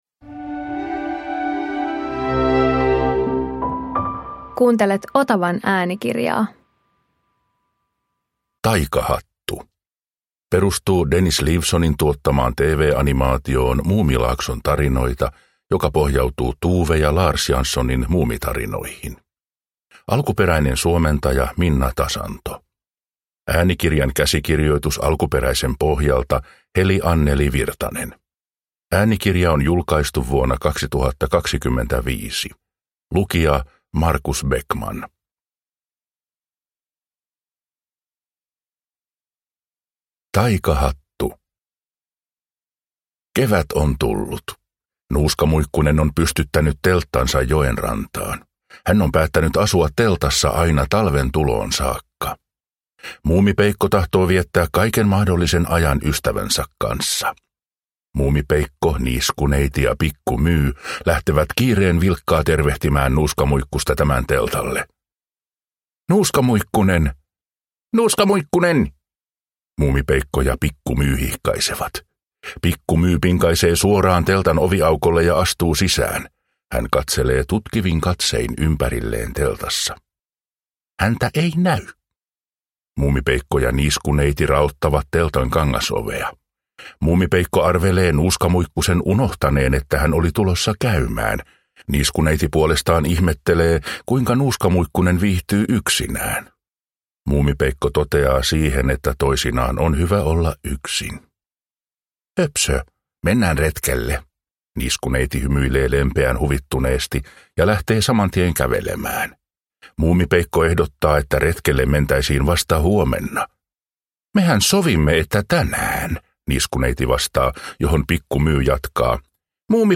Muumi - Taikahattu – Ljudbok